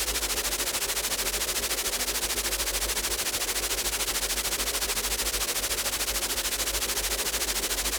Index of /musicradar/stereo-toolkit-samples/Tempo Loops/120bpm
STK_MovingNoiseC-120_03.wav